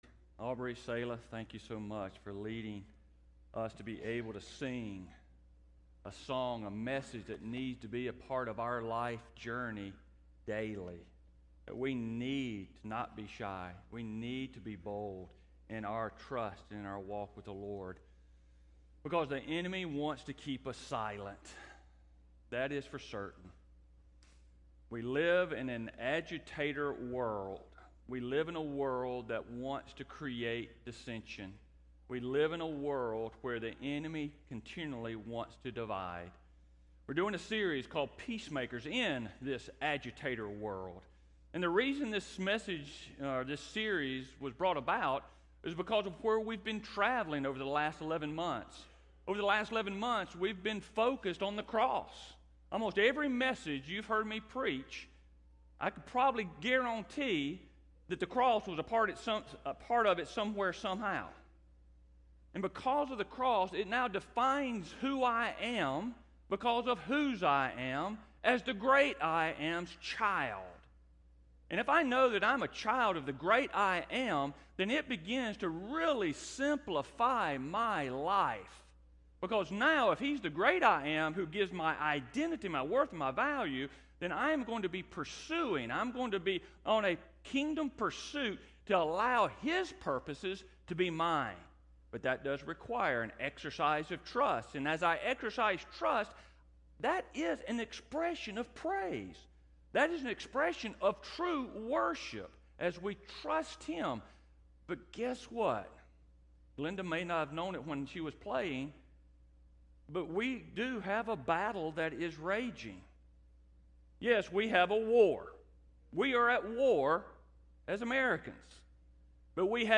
Service Type: Sunday Morning Topics: Peace , Unity